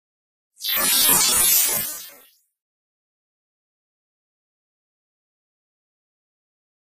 Robot High Frequency Robot Communique